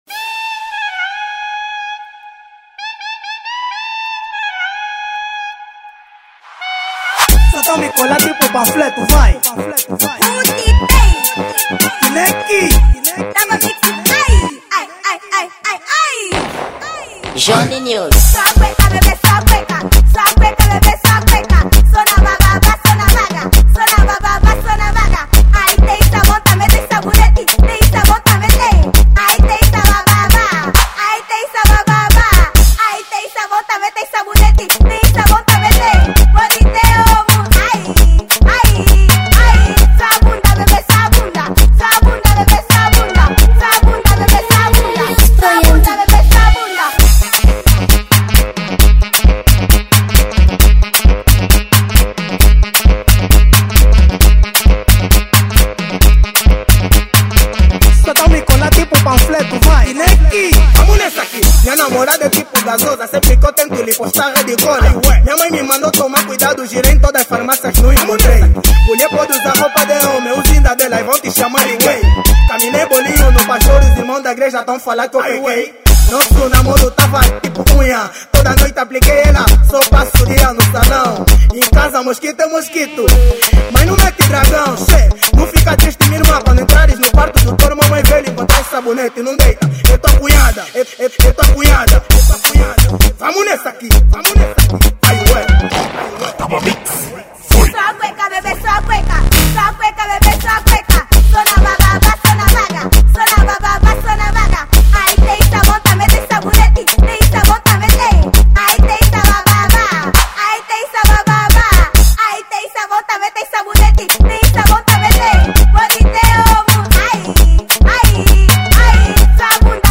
Afro House
Gênero: Dance Hall